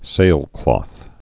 (sālklôth, -klŏth)